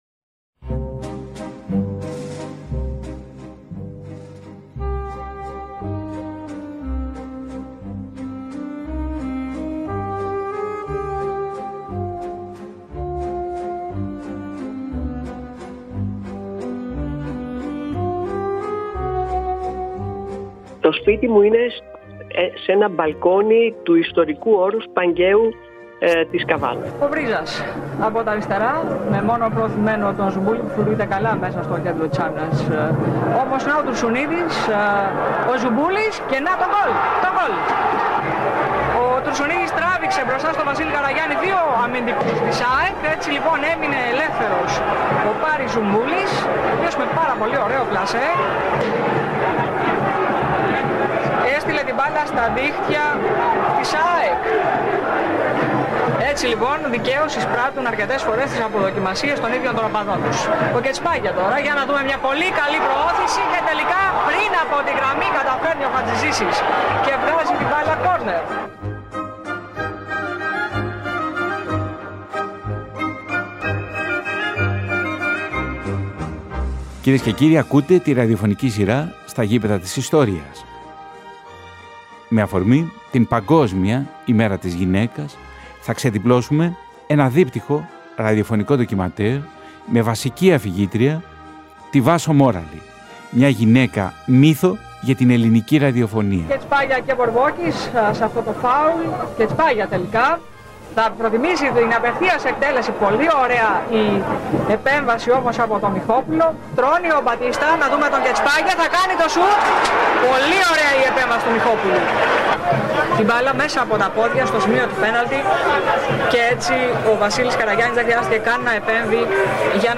Η ΕΡΑ ΣΠΟΡ τιμά την Παγκόσμια Ημέρα της Γυναίκας με ένα δίπτυχο ραδιοφωνικό ντοκιμαντέρ